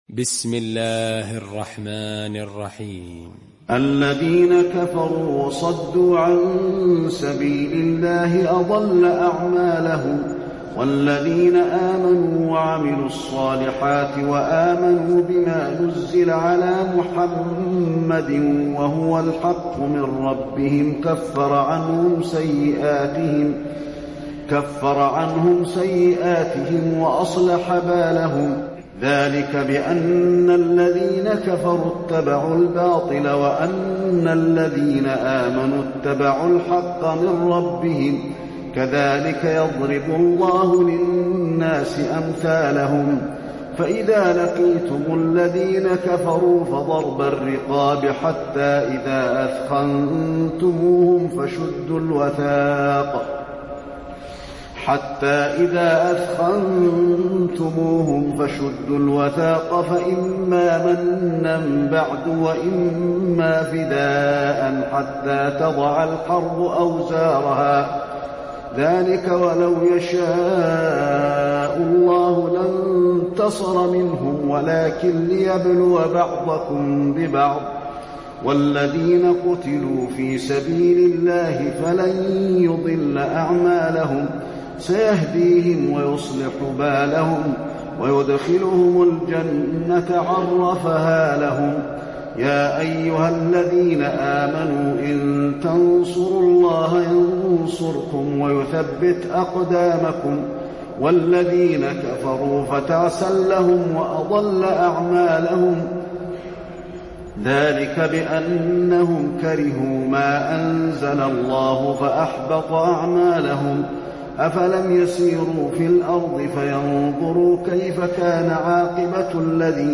المكان: المسجد النبوي محمد